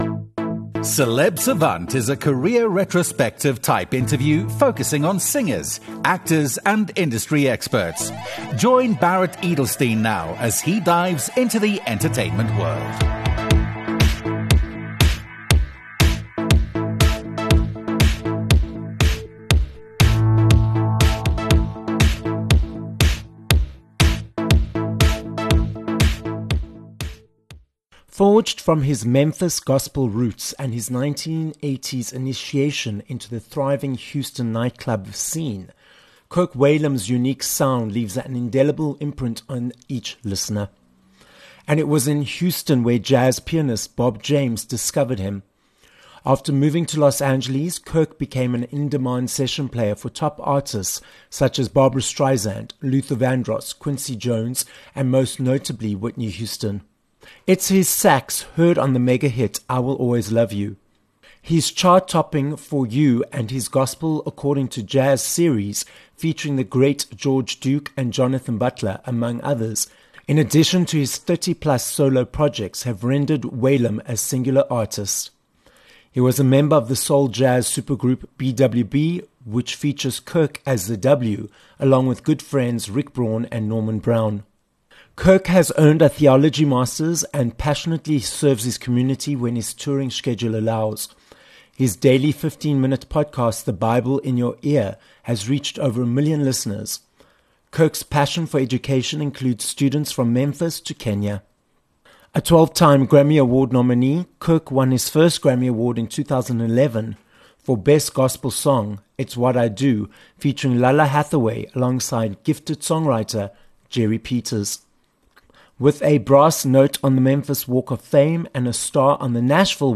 19 May Interview with Kirk Whalum
Having worked with the likes of Barbra Streisand, Luther Vandross, and most notably Whitney Houston as a session musician, we are joined on this episode of Celeb Savant by American saxophonist and Grammy Award winner, Kirk Whalum. Kirk tells us how he was influenced by his famous musician uncle, Hugh Peanuts Whalum, to start playing the sax at the age of 12… leading to a multi-award-winning and multi-decade success in the music industry..